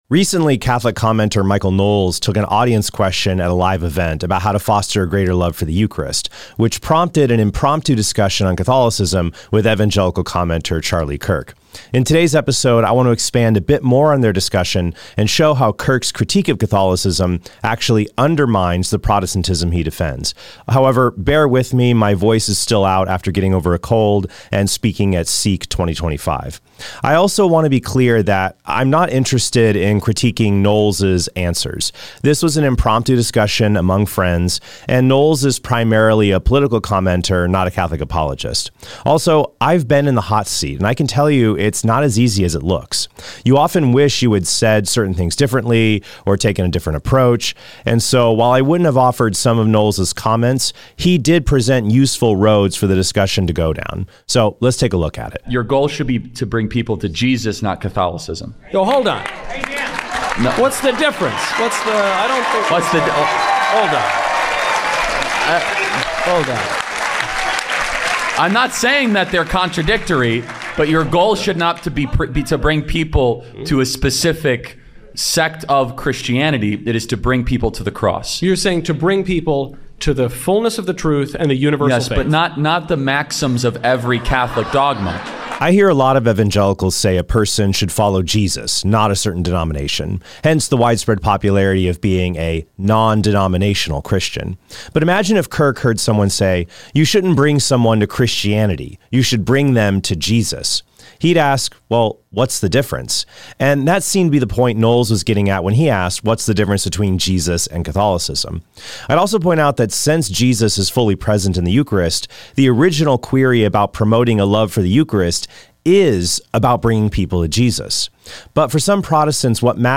However, bear with me, my voice is still out after getting over a cold and speaking at Sikh 2025.